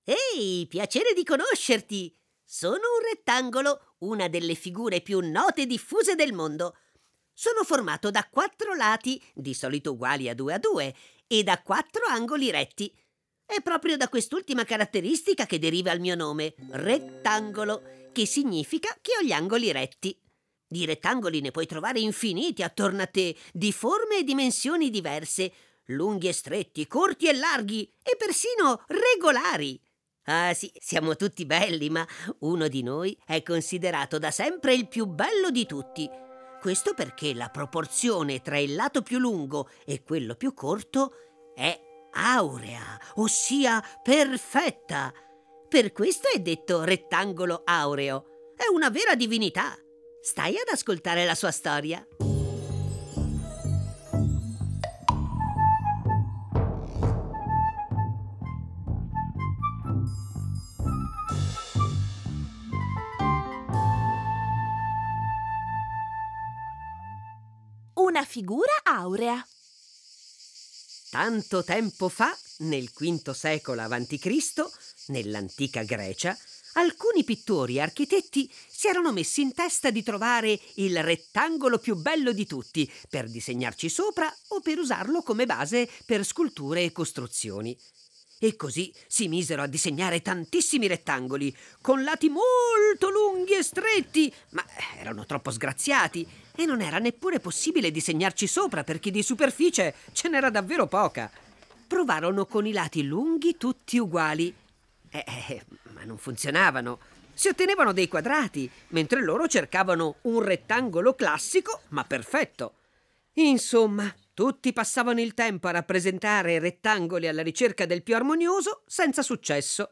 Lettrice:
Lettore:
Piccole lettrici:
Piccoli lettori: